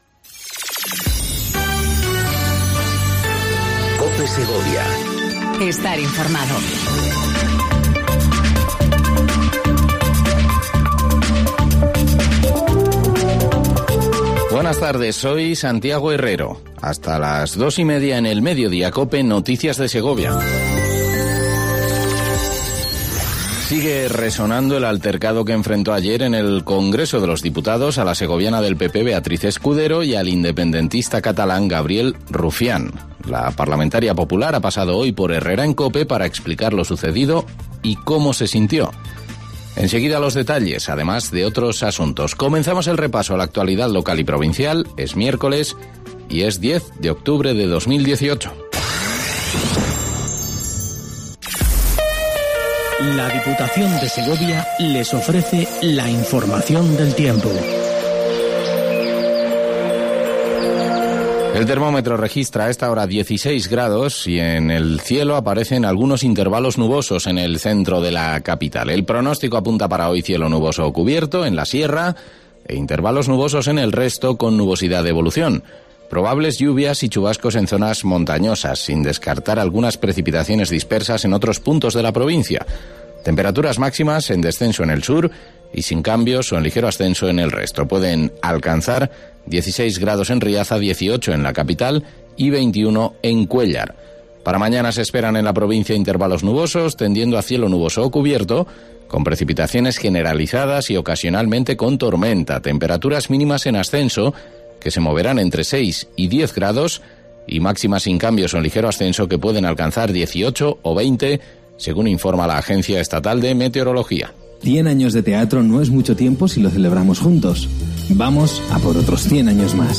INFORMATIVO DE MEDIODÍA EN COPE SEGOVIA 14:20 DEL 10/10/18